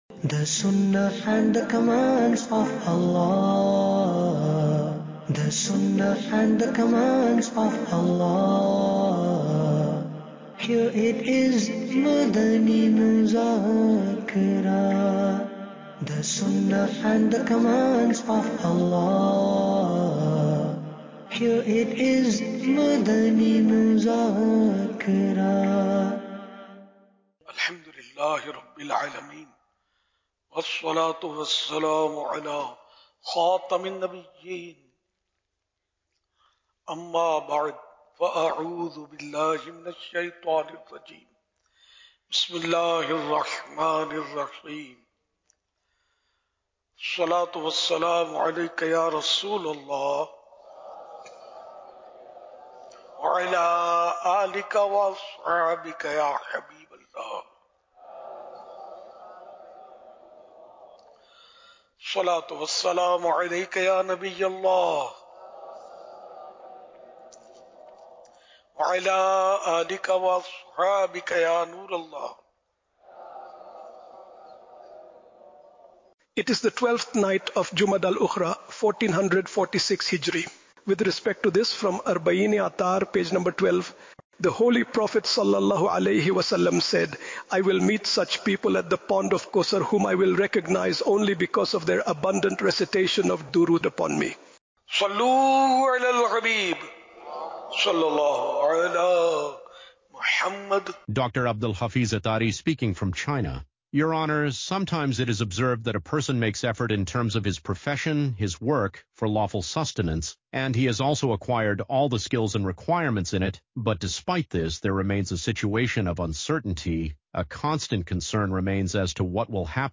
Madani Muzakra – To Whom Can We Relate Our Dreams? – English Dubbed